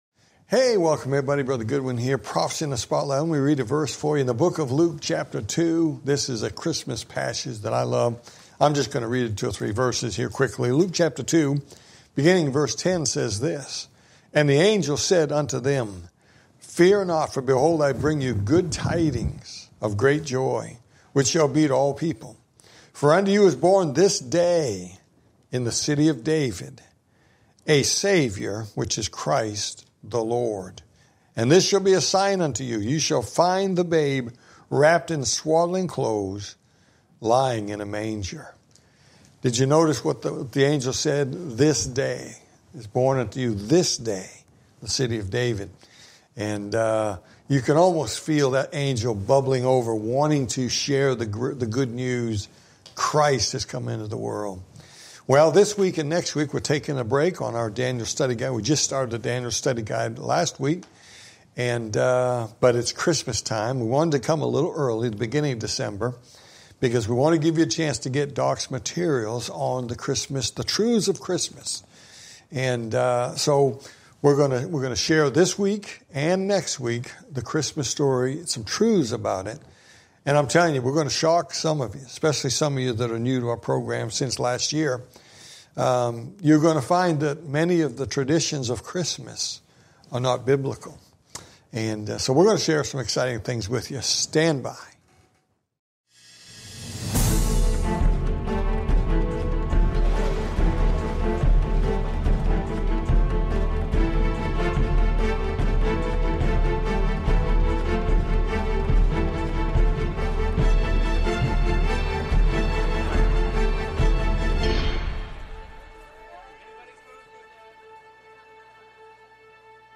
Talk Show Episode, Audio Podcast, Prophecy In The Spotlight and Truths Of Christmas Pt1, Ep226 on , show guests , about Truths Of Christmas, categorized as History,News,Politics & Government,Religion,Society and Culture,Theory & Conspiracy